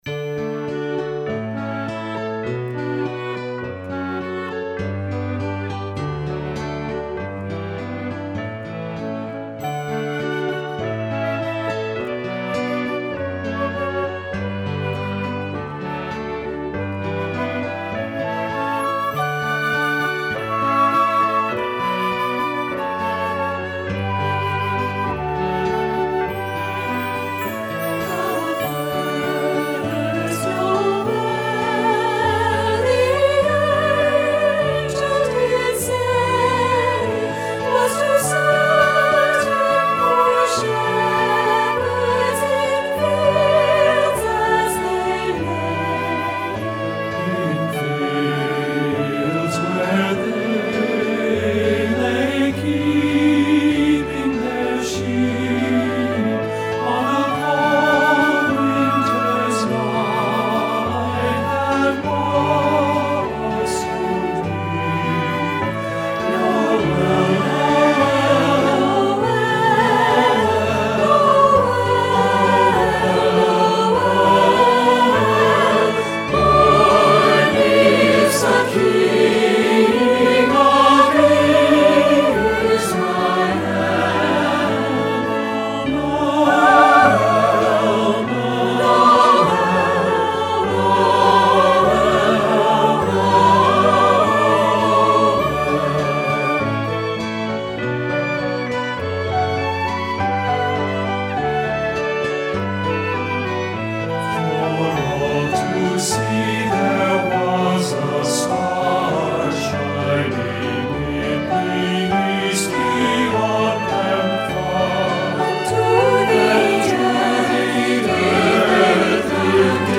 secular choral
TBB (SATB recording)